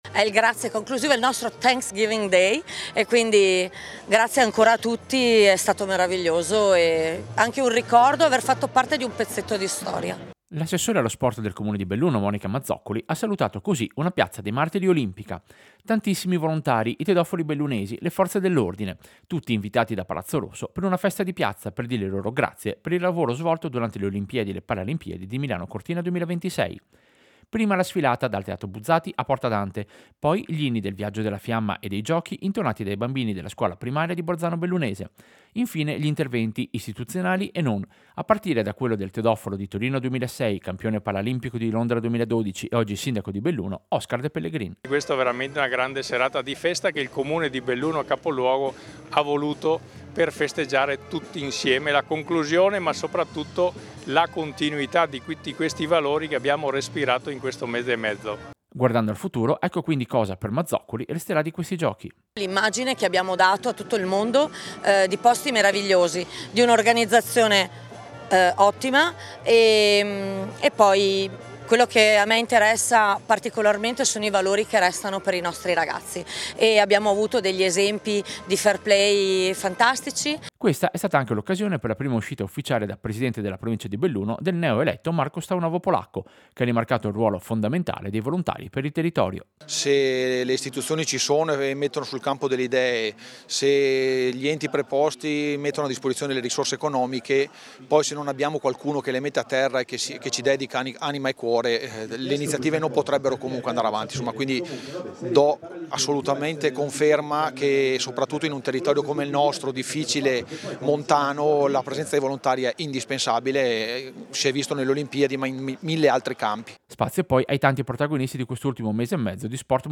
Servizio-Festa-volontari-olimpici-Belluno-1.mp3